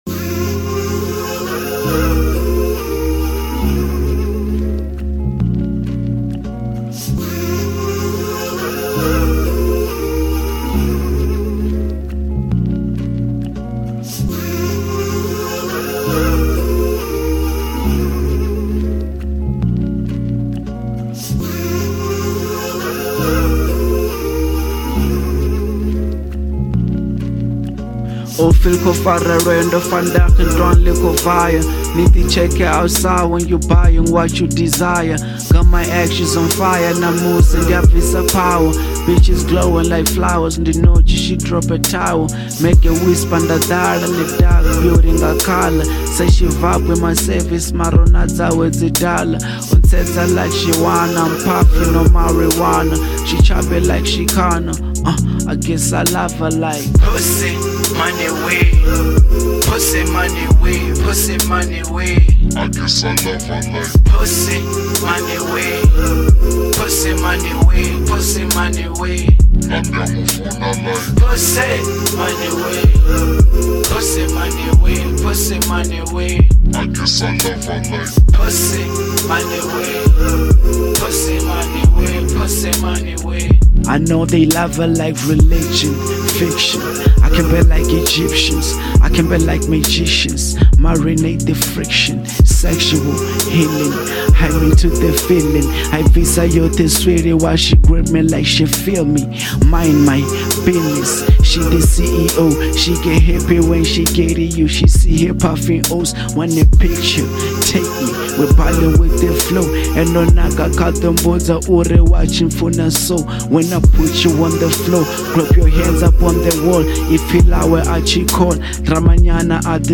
03:04 Genre : Venrap Size